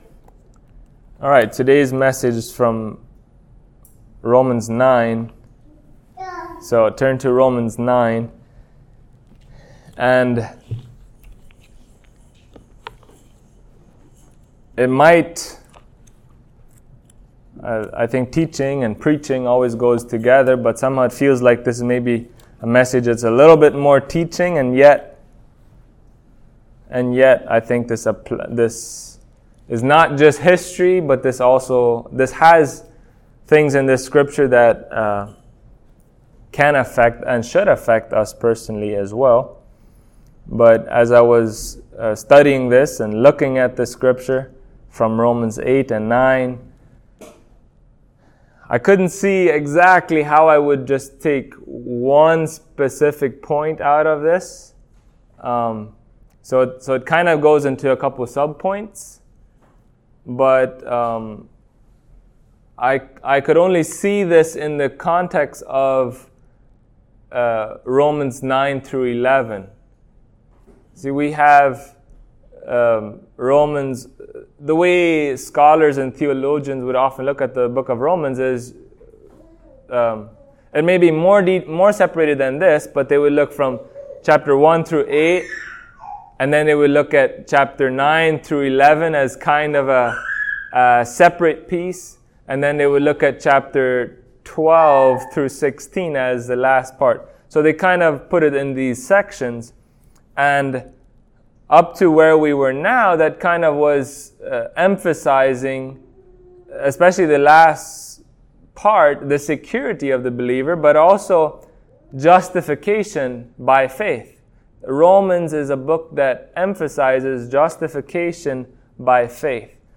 Passage: Romans 9:1-5 Service Type: Sunday Morning